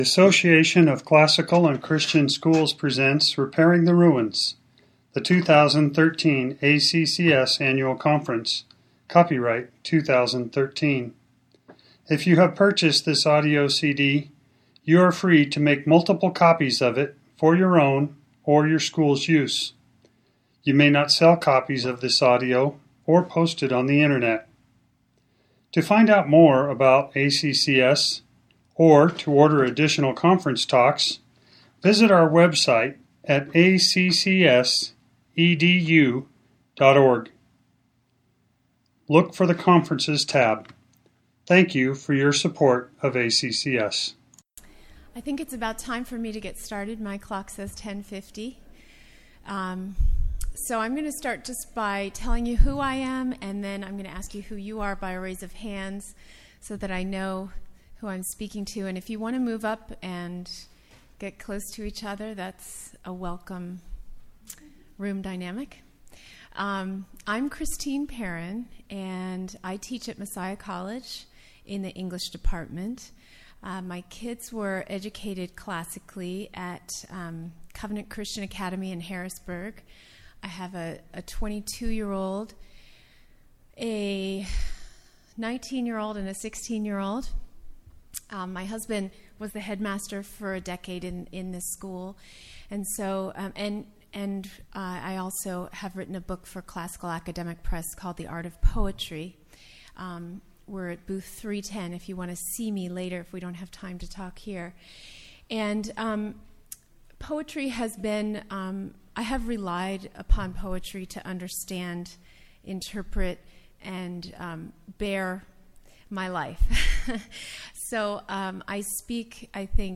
2013 Workshop Talk | 1:03:43 | All Grade Levels, Literature
The Association of Classical & Christian Schools presents Repairing the Ruins, the ACCS annual conference, copyright ACCS.